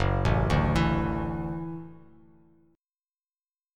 F#m7#5 chord